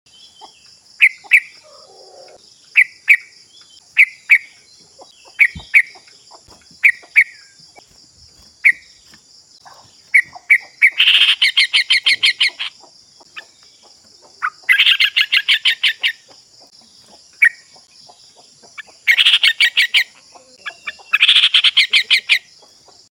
Suara Panggilan Trucukan Muda NGALAS. sound effects free download
Suara Trucuk Muda Bikin Semua Trucuk EMOSI Pancingan Trucukan Gacor Ropel ini bisa menaikkan Emosi trucuk muda trucuk sejenis apapun kalo dengar ini akan ikut bunyi ( trucuk malas , trucuk giras, akan ikut bunyi )